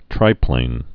(trīplān)